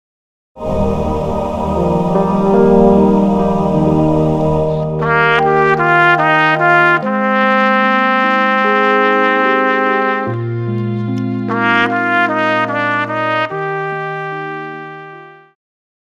Pop
Trumpet
Band
POP,Classical Rearrangement
Instrumental
Ballad
Only backing